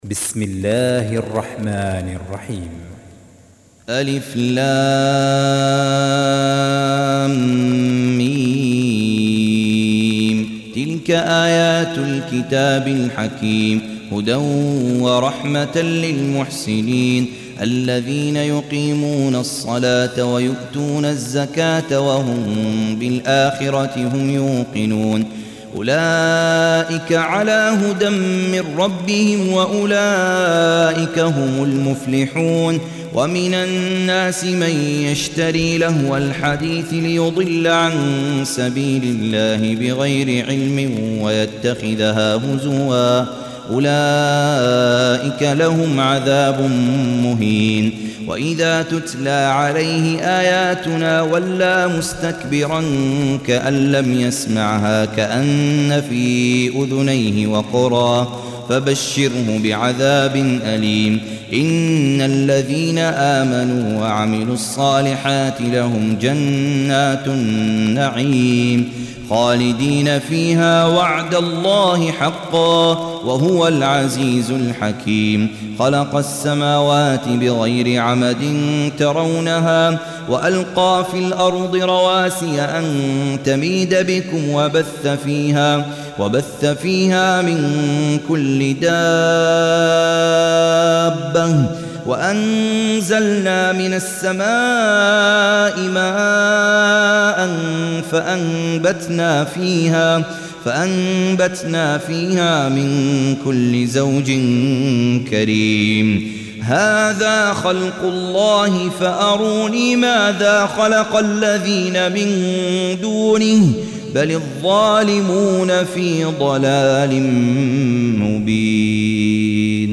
Surah Repeating تكرار السورة Download Surah حمّل السورة Reciting Murattalah Audio for 31. Surah Luqm�n سورة لقمان N.B *Surah Includes Al-Basmalah Reciters Sequents تتابع التلاوات Reciters Repeats تكرار التلاوات